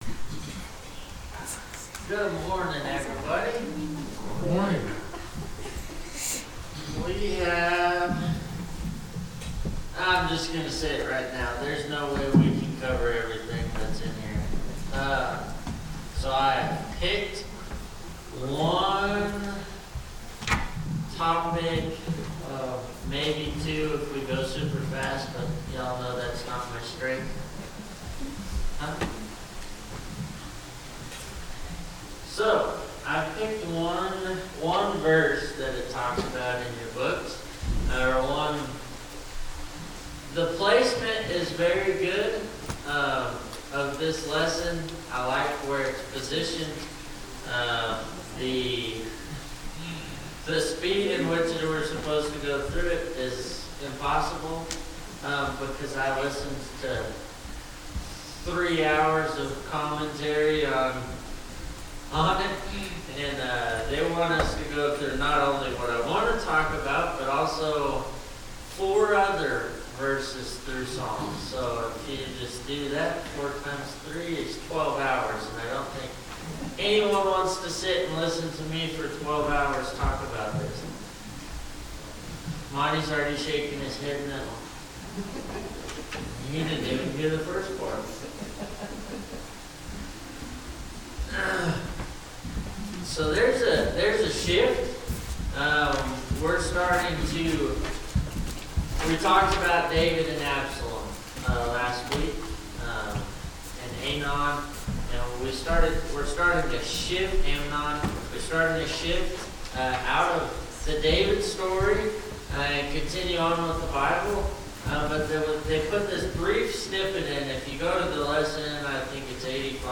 Bible Class 04/27/2025 - Bayfield church of Christ
Sunday AM Bible Class